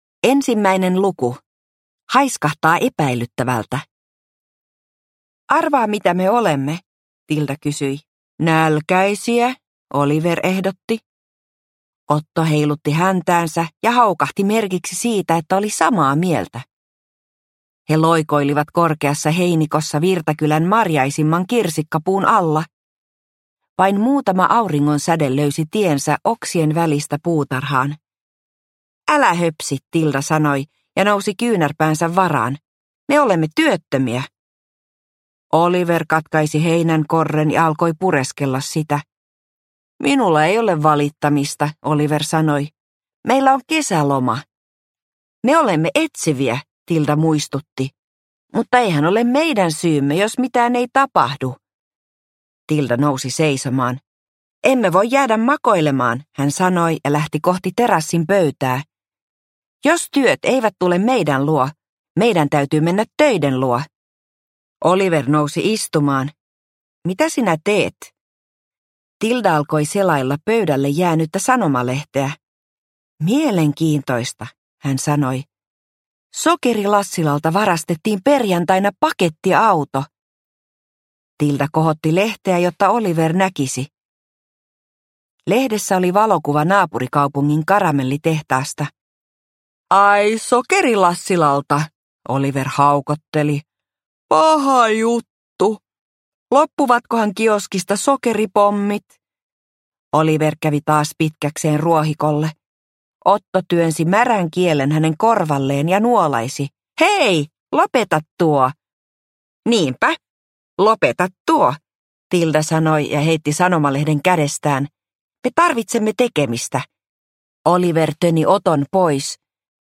Operaatio Myrskypilvi – Ljudbok – Laddas ner